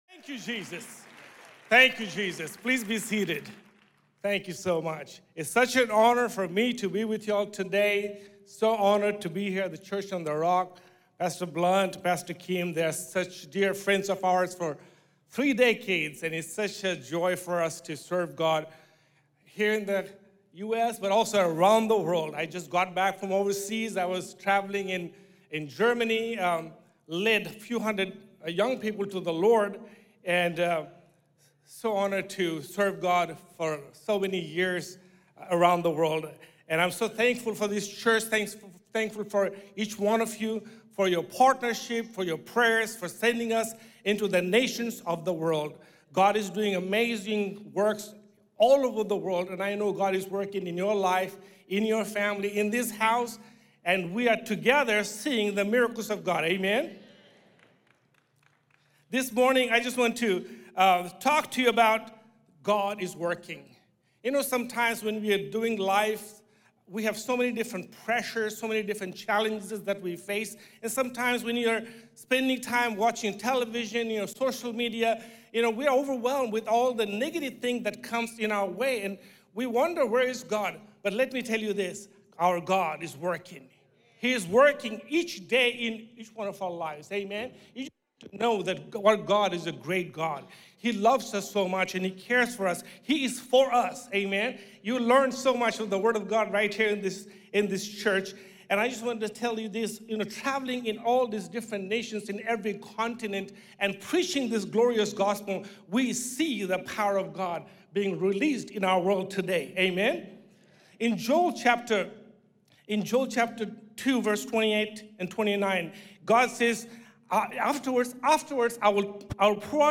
Deja que este mensaje estimule tu fe para que veas que eres parte de la gran cosecha de Dios, justo donde estás.